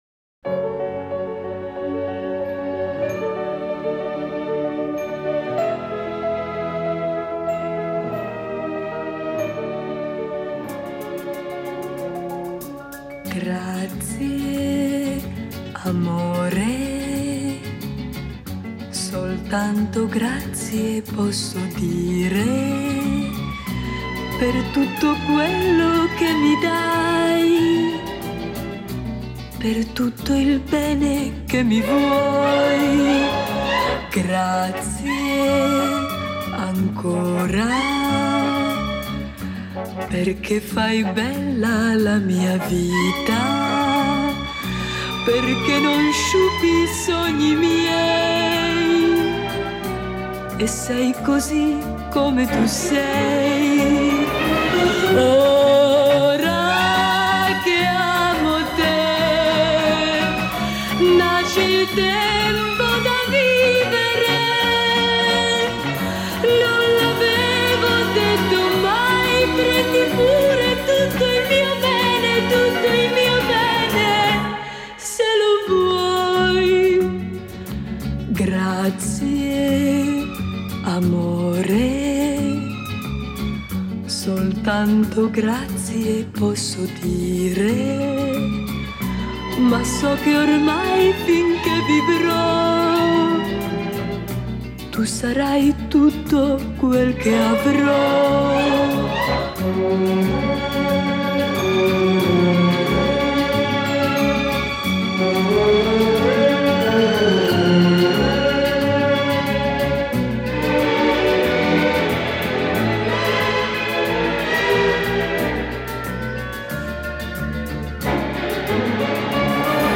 Genre: Pop, oldies